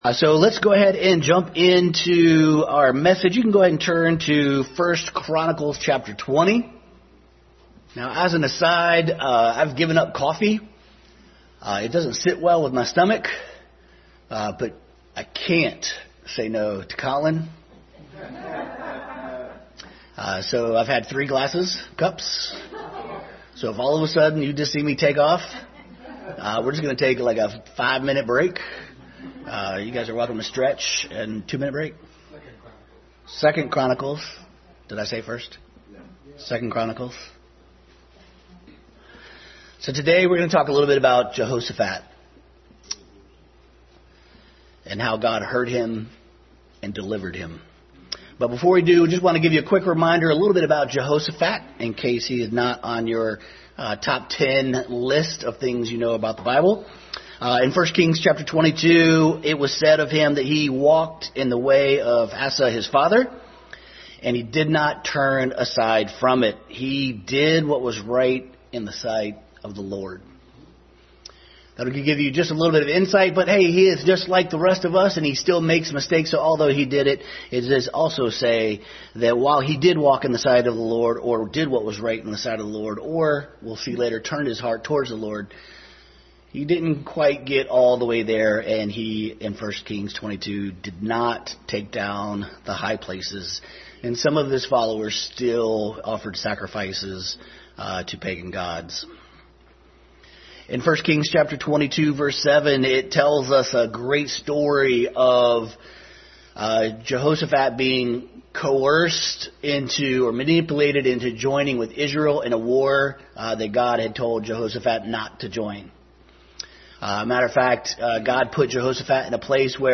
Jehoshaphat Passage: 2 Chronicles 20:1-37, 1 Kings 22:7, Philippians 4:6, 1 Peter 5:6, James 5:16, 2 Corinthians 12:7 Service Type: Family Bible Hour